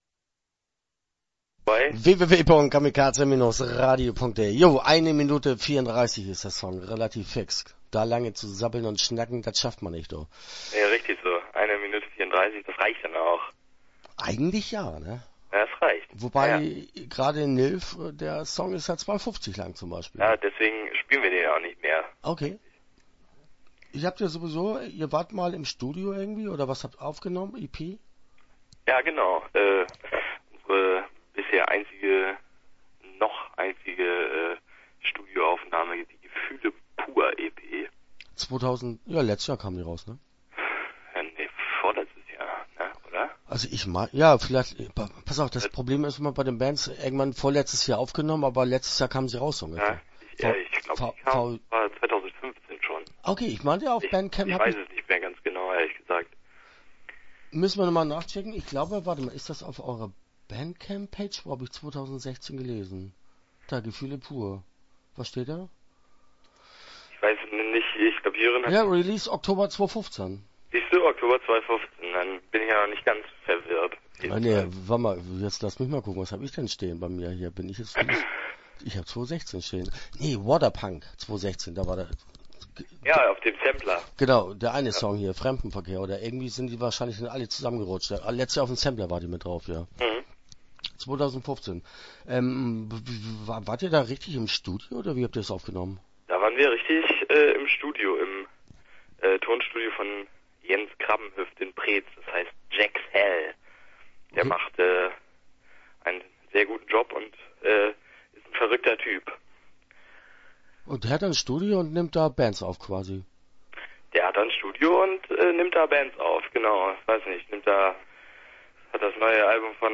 Gordon Shumway - Interview Teil 1 (10:15)